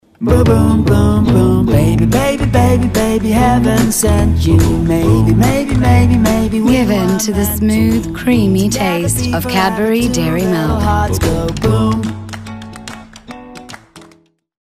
Demo commercial – Cadburys